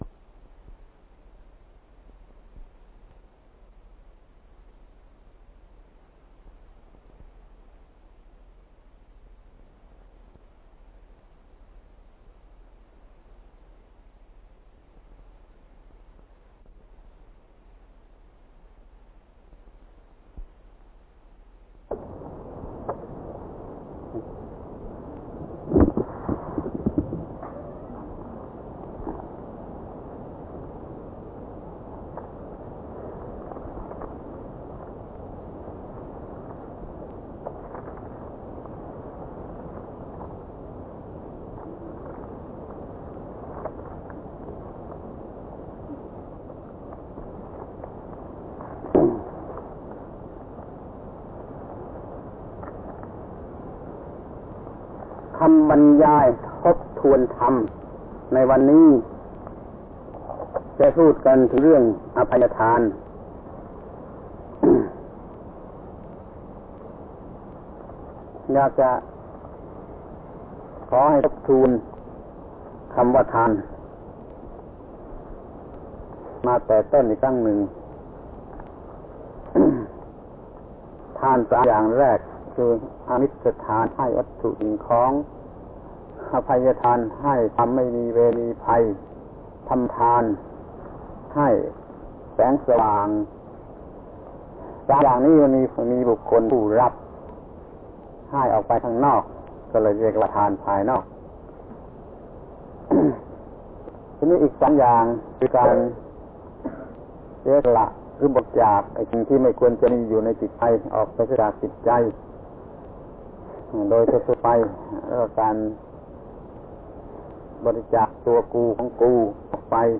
พระธรรมโกศาจารย์ (พุทธทาสภิกขุ) - ทบทวนธรรมบรรยายภิกษุ ปี 2513 ครั้งที่ 3 เรื่อง อภัยทาน